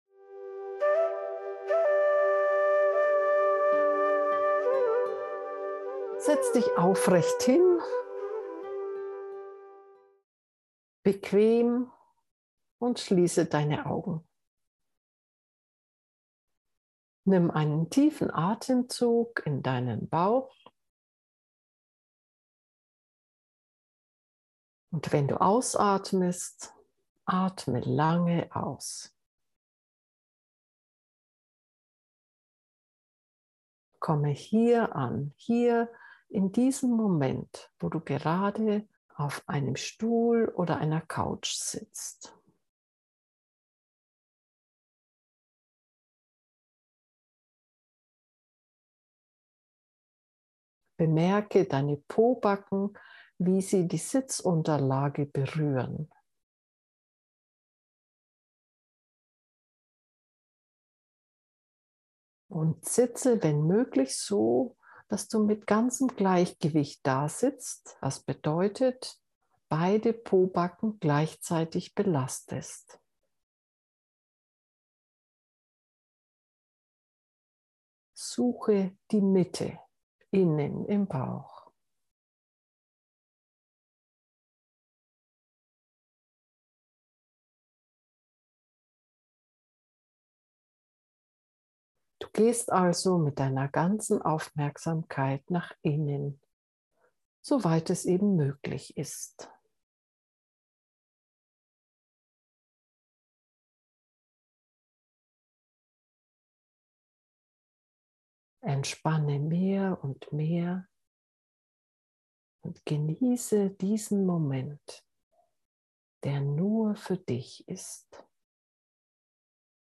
Oktober 2022 Meditationsanleitung zur geführten Meditation Wenn du kurz nach innen geführt werden möchtest, dann höre dieser Anleitung zur Nasenspitzen Meditation zu.